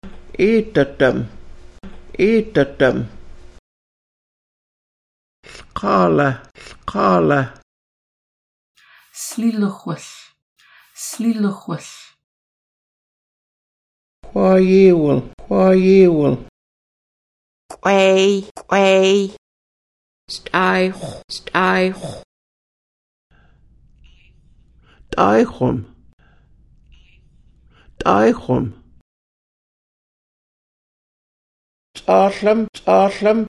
Listen to the elder
Audio Vocabulary and Phrases